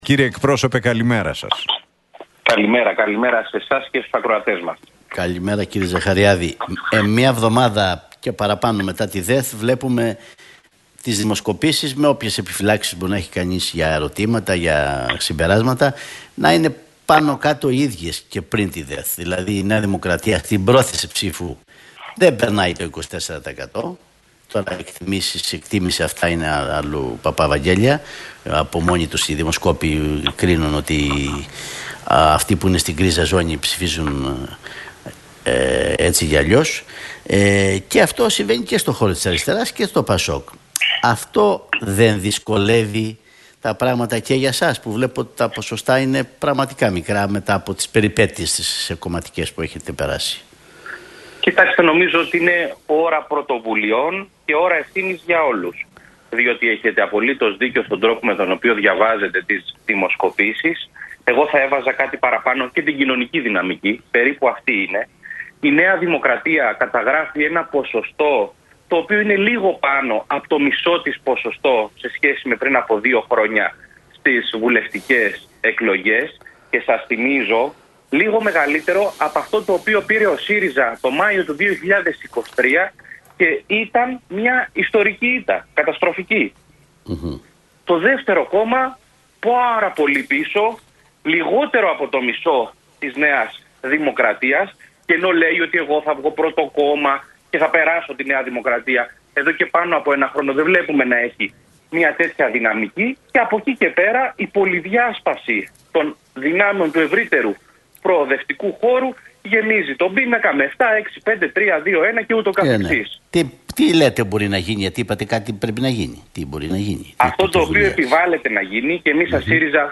Ο εκπρόσωπος Τύπου του ΣΥΡΙΖΑ, Κώστας Ζαχαριάδης, σε συνέντευξή του στον Realfm 97,8, αναφέρθηκε στην ανάγκη για ανασυνθετικές πρωτοβουλίες στην